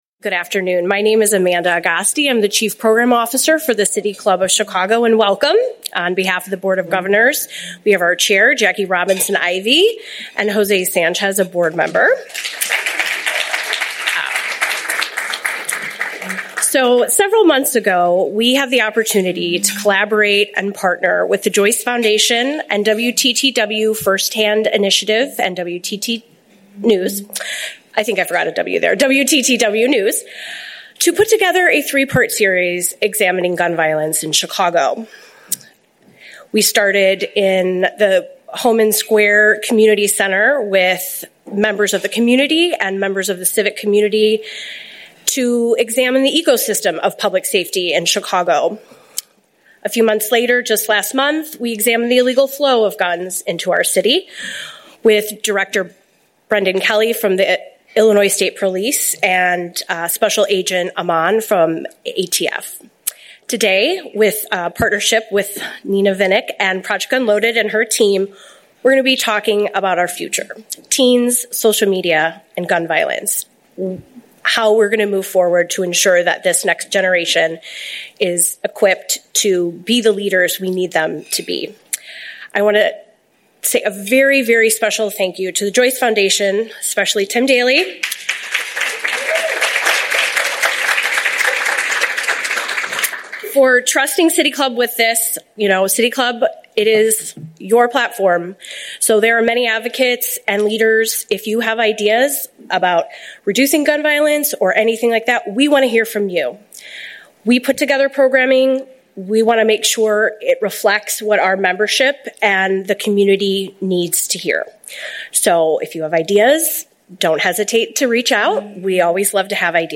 City Club event